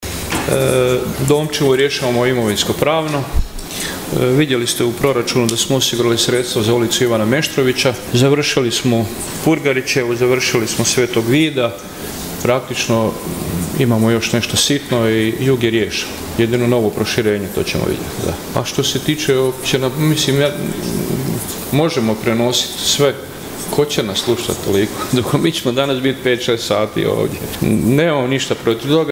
Aktualni sat 6. sjednice Gradskog vijeća Grada Nova Gradiška i ovoga je puta bio prilika gradskim vijećnicima da postave pitanja vezana uz svakodnevno funkcioniranje grada i rad gradske uprave. Na sva postavljena pitanja odgovarao je gradonačelnik Vinko Grgić.